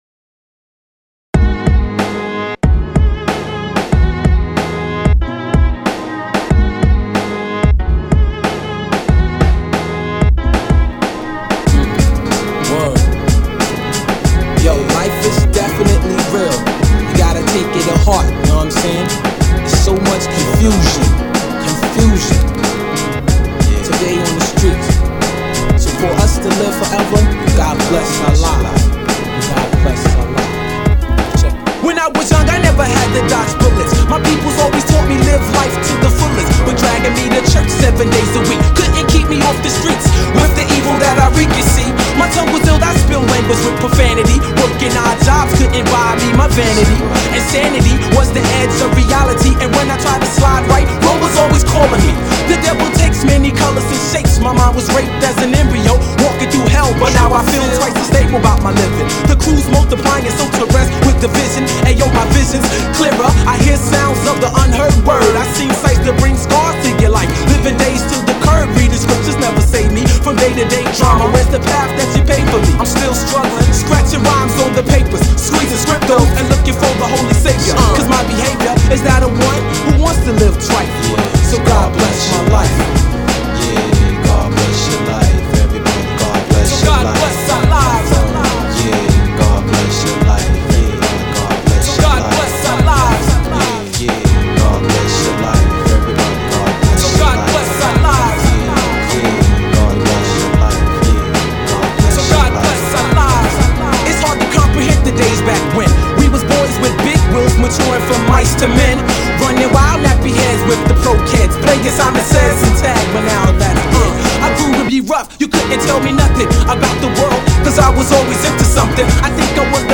he creates mash-ups and sometimes his own beats.